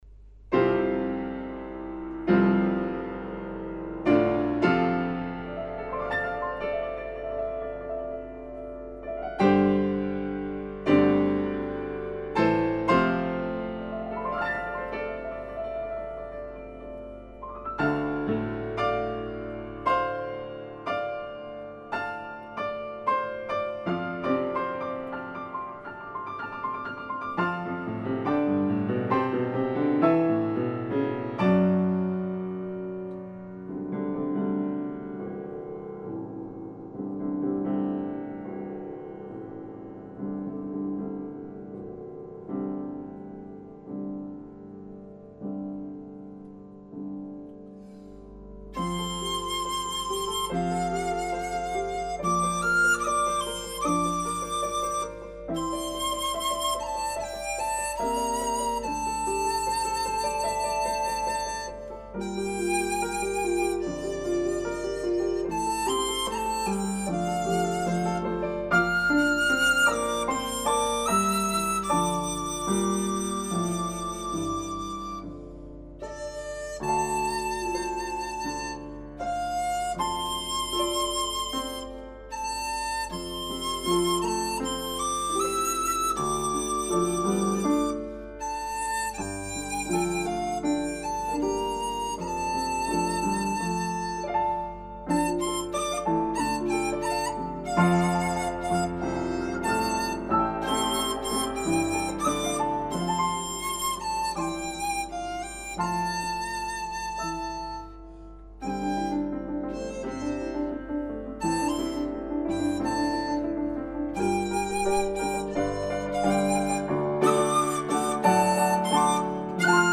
伴奏範本僅作參考，不可用作參賽用途，參賽者應以鋼琴現場伴奏。
• 頊目一：粵語